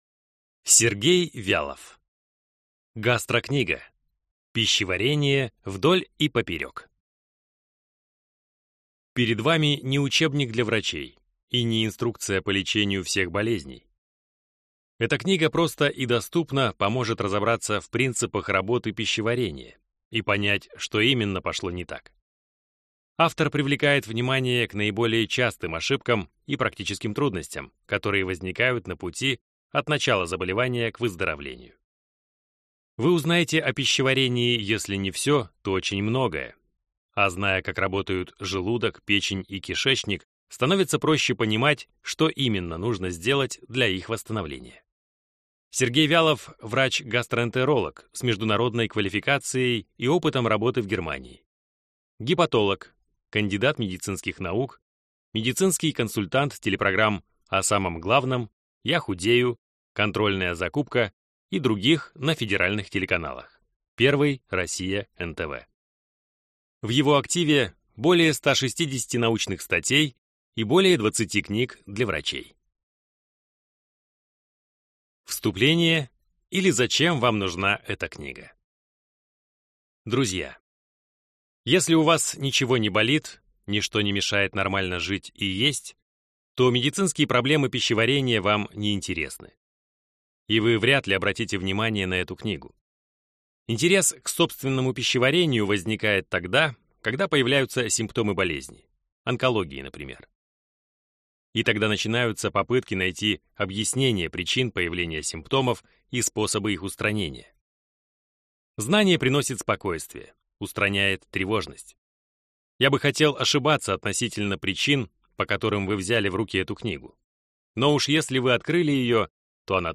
Аудиокнига Гастро-книга. Пищеварение вдоль и поперек | Библиотека аудиокниг